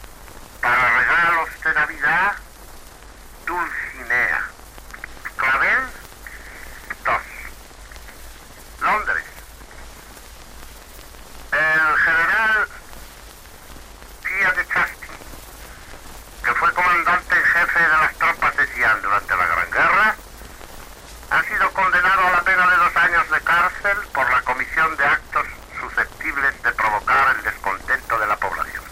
Publicitat, informació de la conedemna a Londres del general que va ser comandant de les tropes de Siam durant la Primera Guerra Mundial
Informatiu